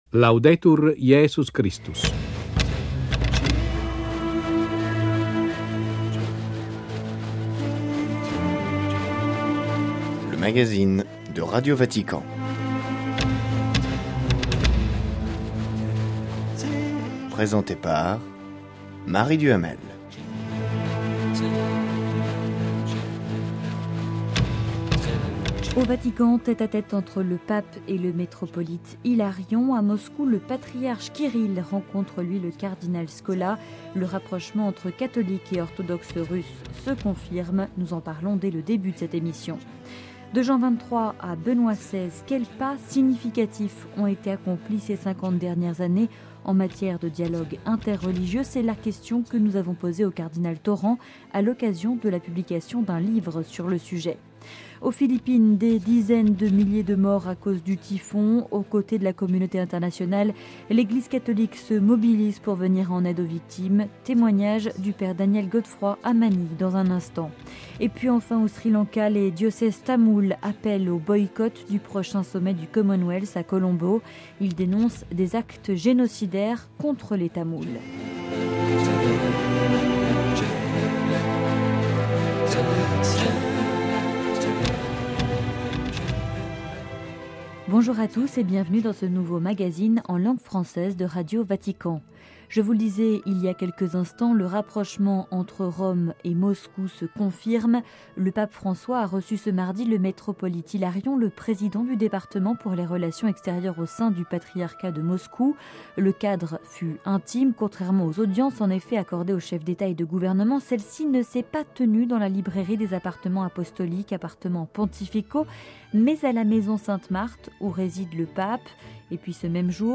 Le rapprochement entre catholiques et orthodoxes russes se confirme. Notre invité : le cardinal Paul Poupard, président émérite du Conseil pontifical pour la Culture.